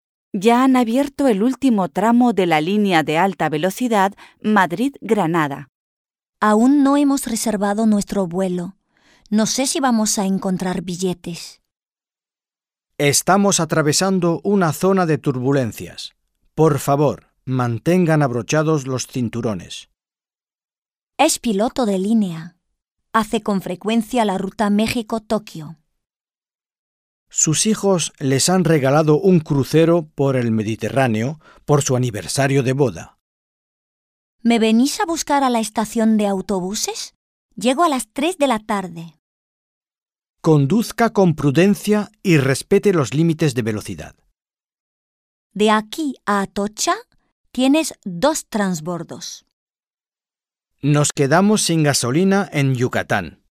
Un peu de conversation - Les moyens de transport